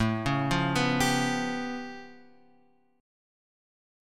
A Augmented 9th